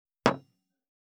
208,机に物を置く,テーブル等に物を置く,食器,グラス,コップ,工具,小物,雑貨,コトン,トン,ゴト,ポン,ガシャン,ドスン,ストン,カチ,タン,バタン,スッ,サッ,コン,
コップ効果音物を置く